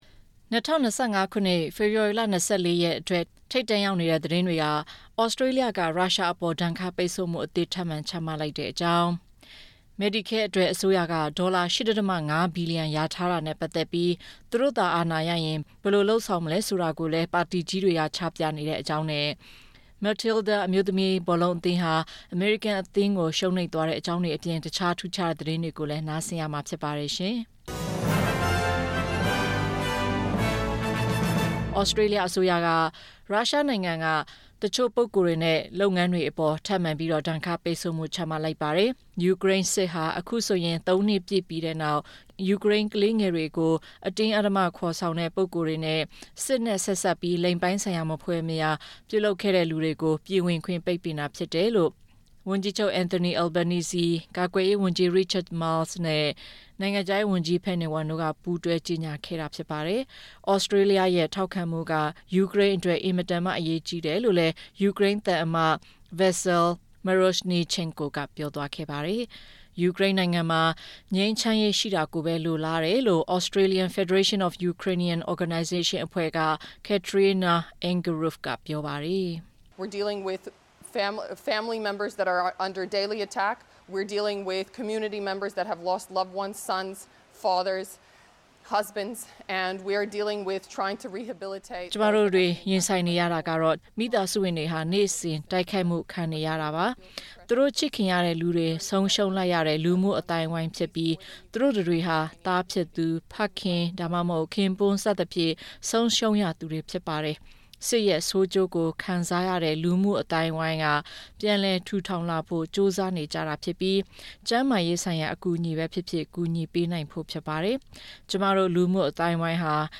၂၀၂၅ ဖေဖော်ဝါရီ ၂၄ လျပ်တပြတ်သတင်းများ